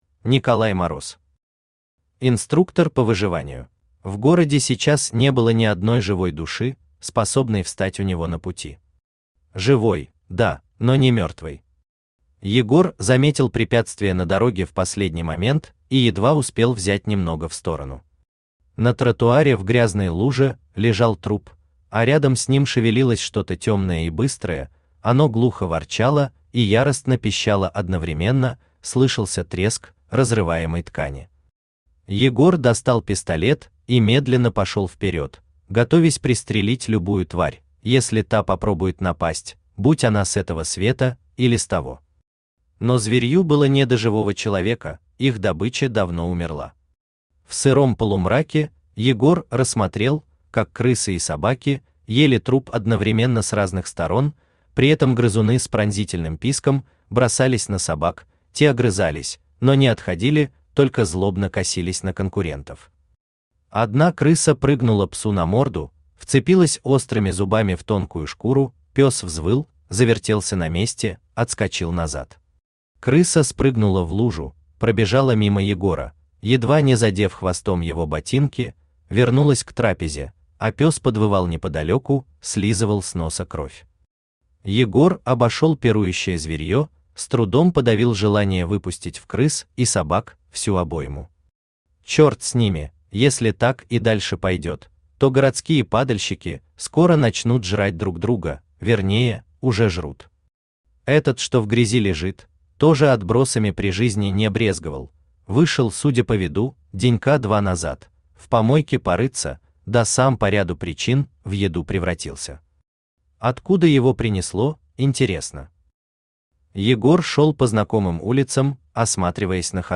Аудиокнига Инструктор по выживанию | Библиотека аудиокниг
Aудиокнига Инструктор по выживанию Автор Николай Мороз Читает аудиокнигу Авточтец ЛитРес.